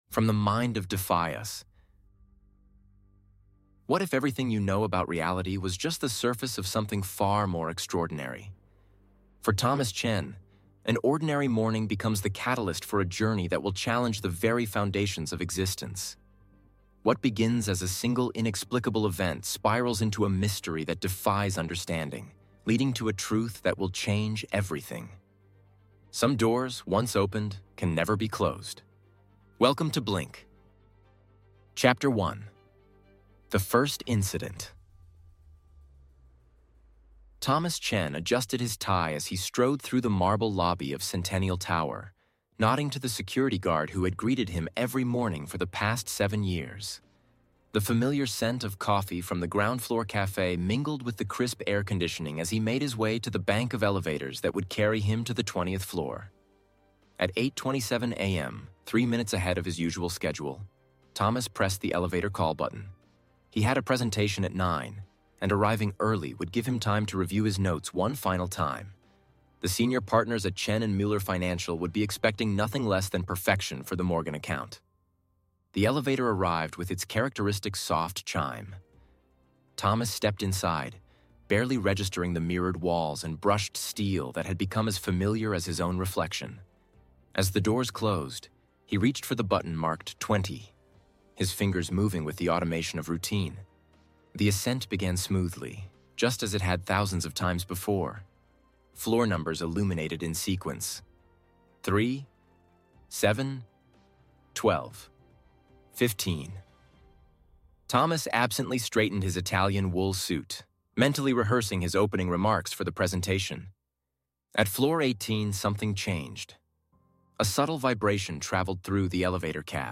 Experience the beginning of BLINK, a groundbreaking science fiction audiobook experience that pushes the boundaries of reality itself. Follow Thomas Chen as his ordinary life takes an extraordinary turn that will challenge everything you thought you knew about existence.